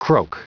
Prononciation du mot croak en anglais (fichier audio)
Prononciation du mot : croak